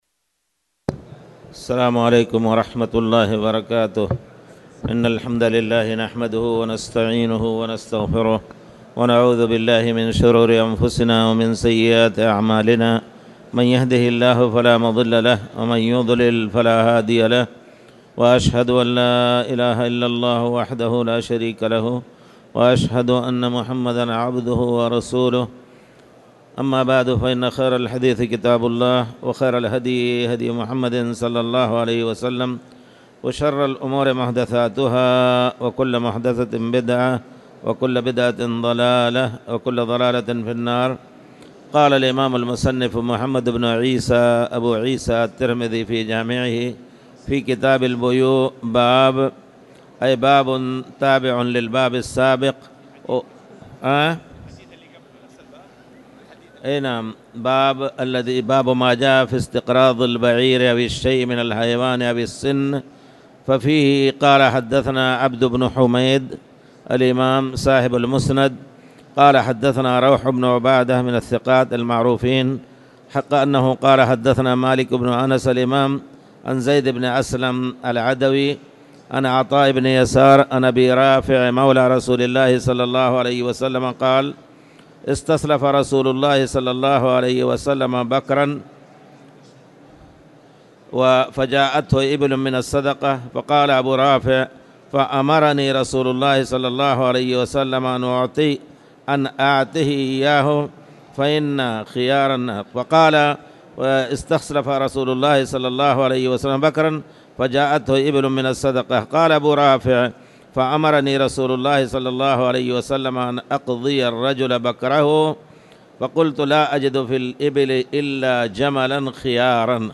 تاريخ النشر ٢١ ربيع الثاني ١٤٣٨ هـ المكان: المسجد الحرام الشيخ